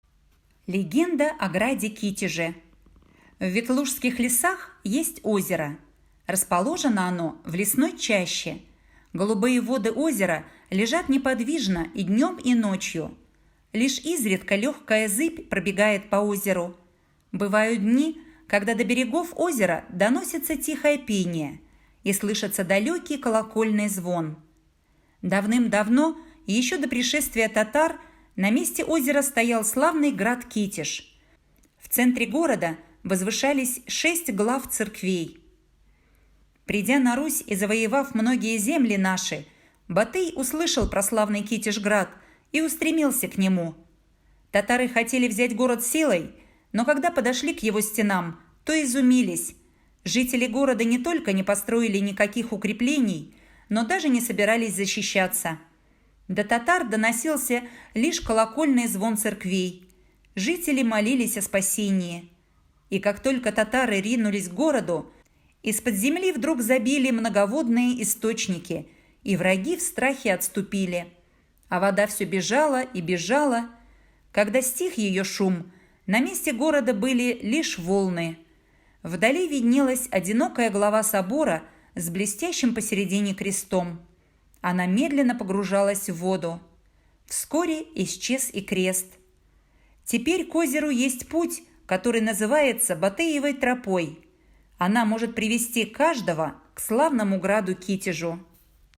Легенда о граде Китеже - аудио былина - слушать онлайн